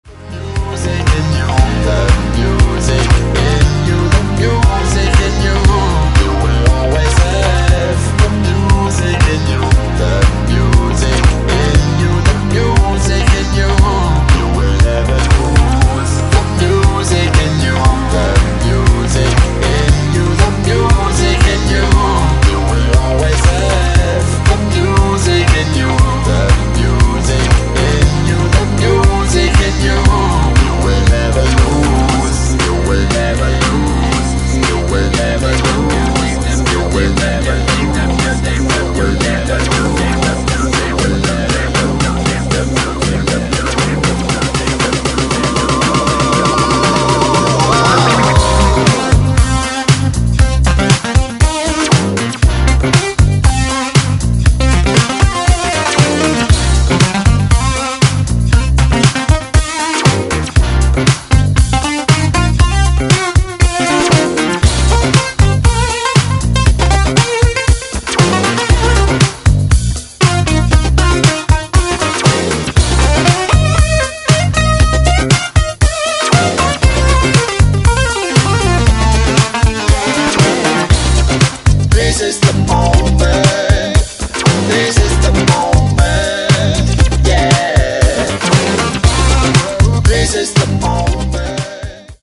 ブギーでソウルフルなディスコ、ファンク、ハウス、を散りばめたグレイト過ぎる好内容です！